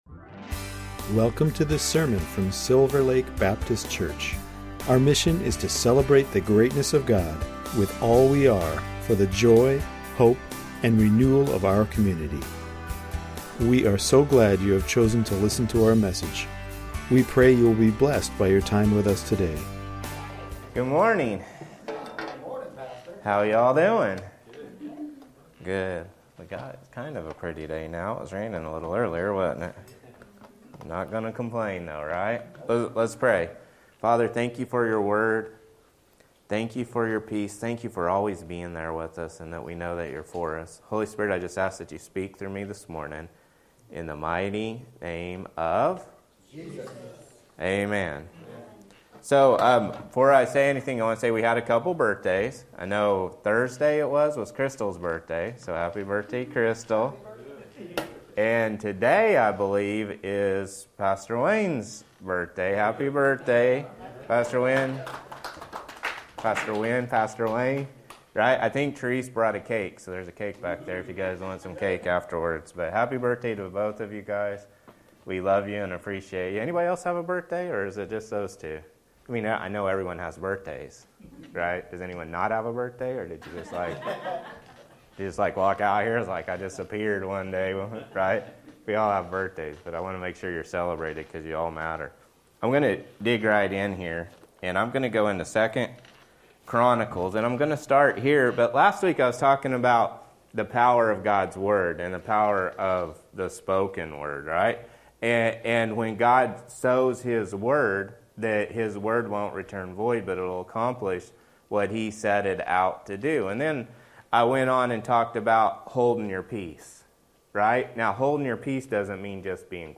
Recent sermons from Silver Lake Baptist Church, Everett, WA